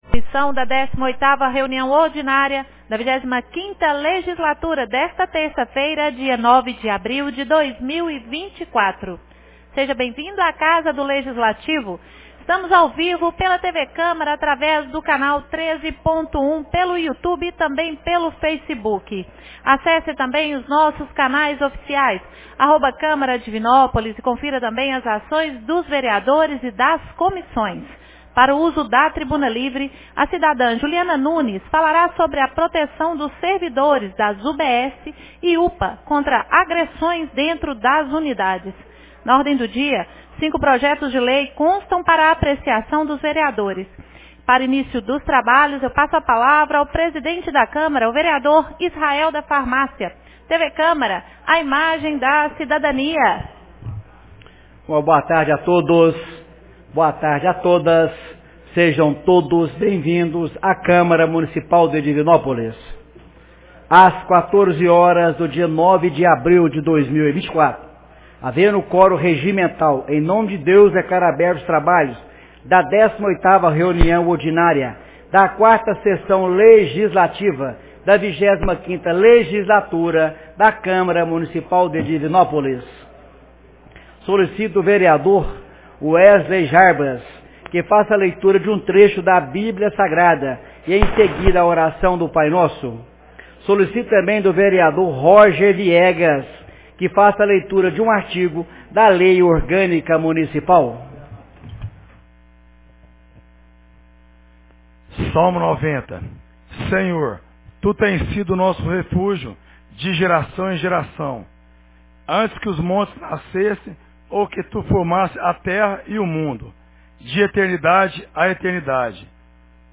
18ª Reunião Ordinária 09 de abril de 2024